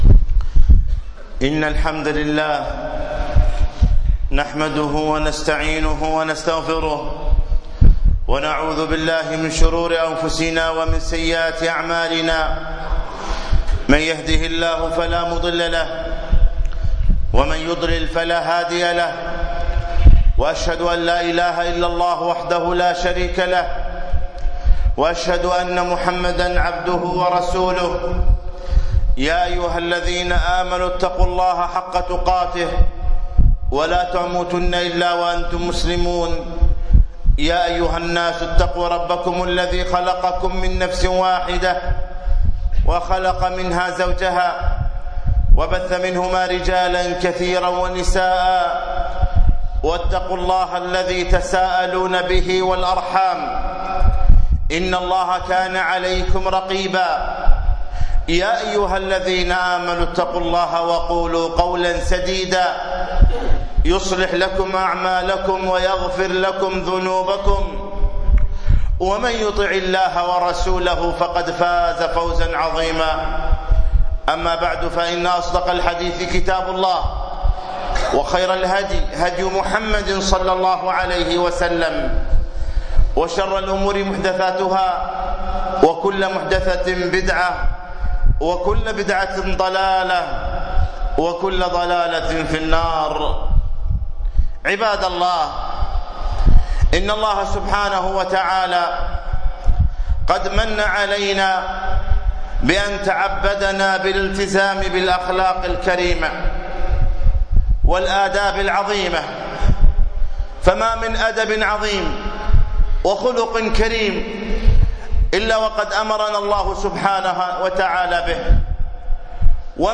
خطبة بتاريخ 3 4 2015